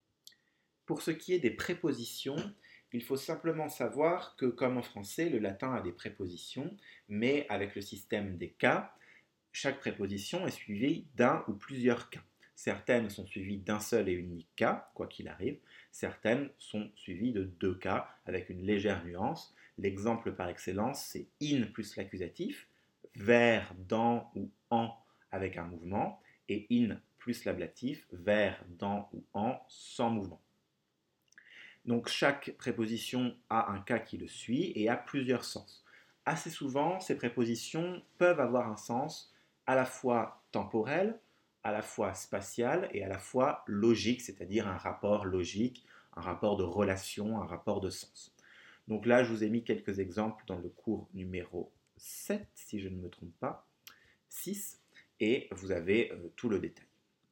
Mémos vocaux pour le latin
Pardon par avance pour les petites hésitations, erreurs de langues et autres zozotements ! =)